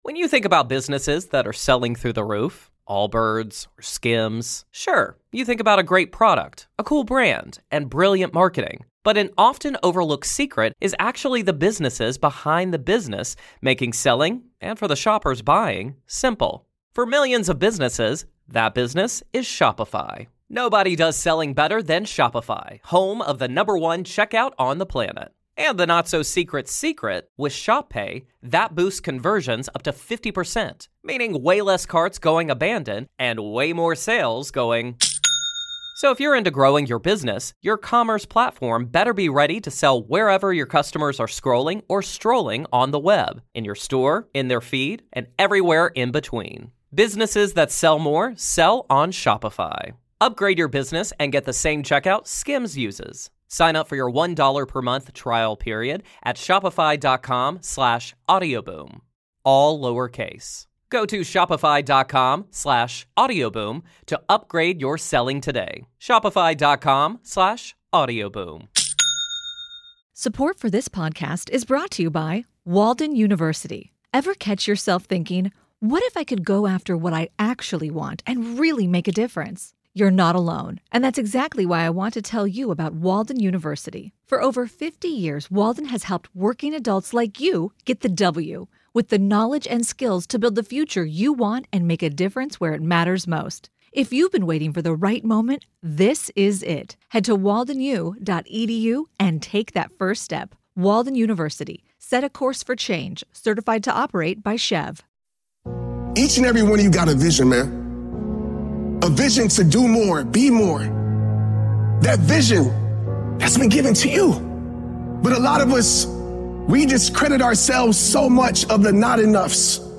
Spoken by Eric Thomas, Les Brown, David Goggins.